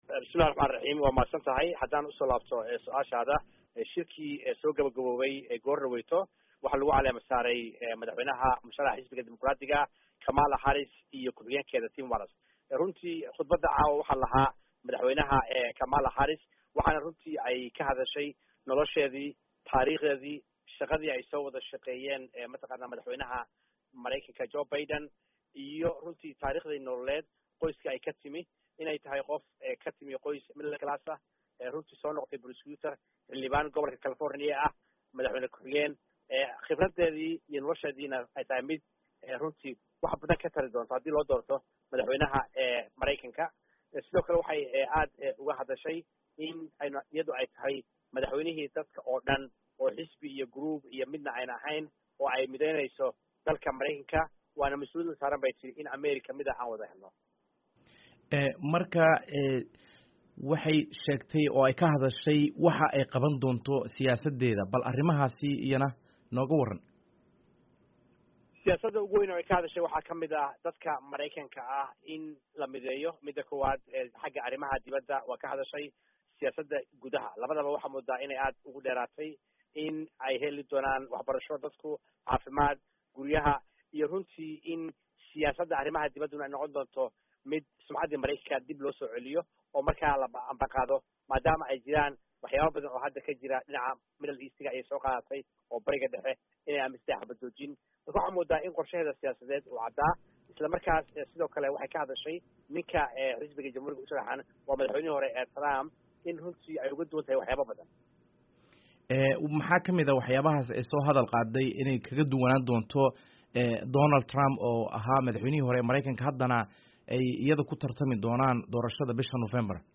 Halkan ka dhageyso mid ka mid ah ergadii ka qeybgashay Shirweynaha Xisbiga Dimuqraadiga